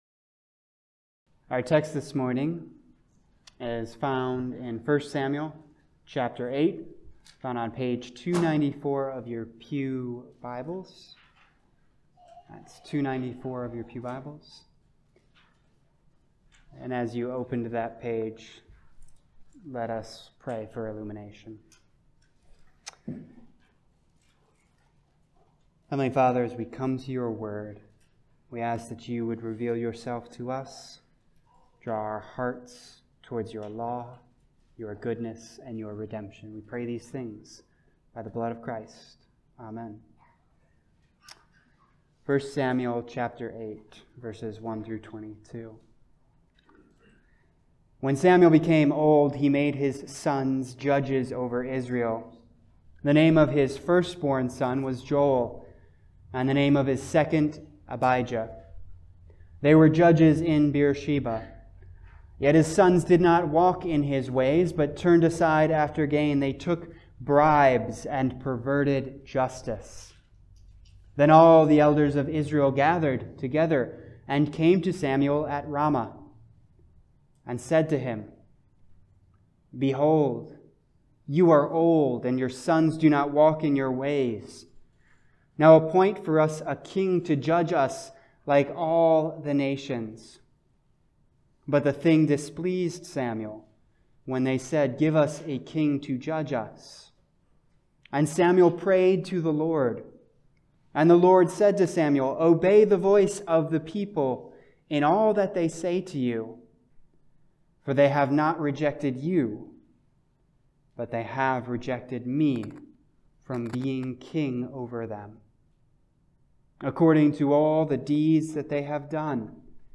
Passage: 1 Samuel 8:1-22 Service Type: Sunday Service « God Glorifies Himself Through His Power and Faithfulness The Spirit